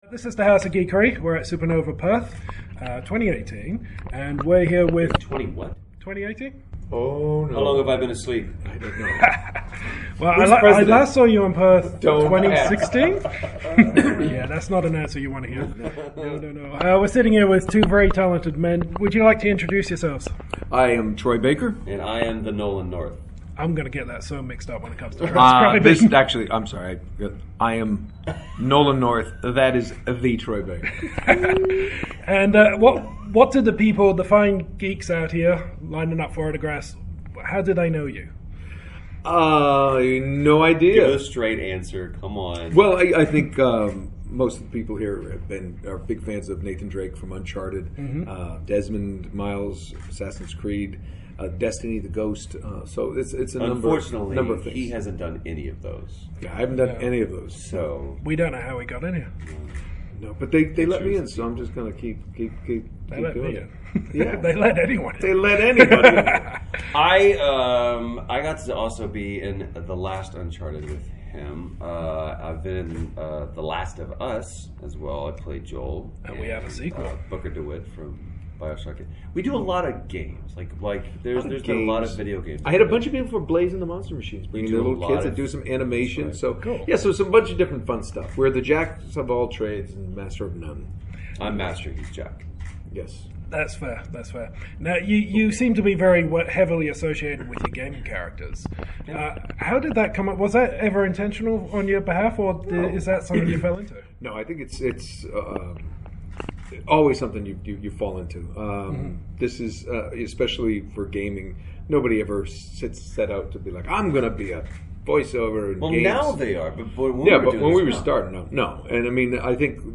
Exclusive Interview with Nolan North and Troy Baker!
This is our last part of the 2018 Supanova coverage, but it’s a good one!
These guys are literally professional talkers and bounce off each other so quickly we strongly suggest using the audio clip below.
troy-baker-and-nolan-north-interview-edit.mp3